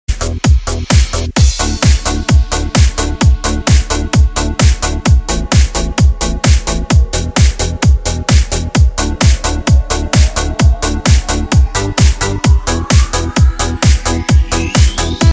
DJ铃声, M4R铃声, MP3铃声 28 首发日期：2018-05-15 04:45 星期二